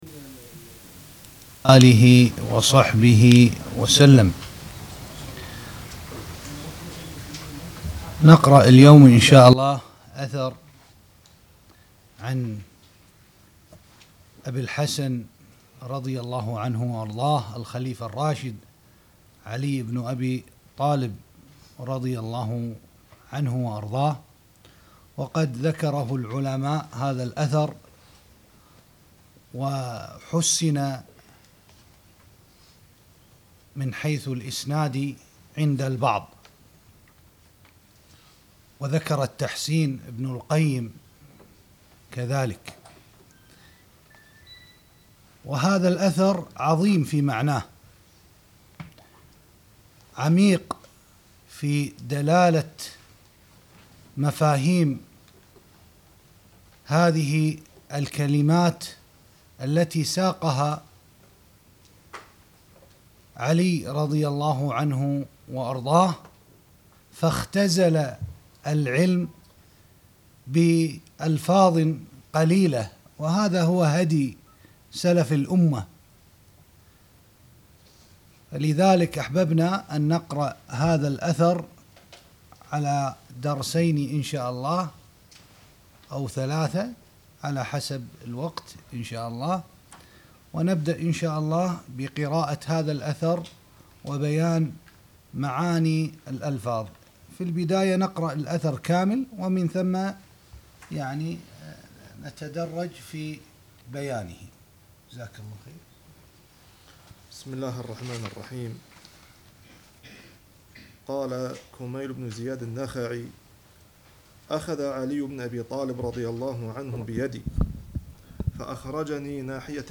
الدروس والمحاضرات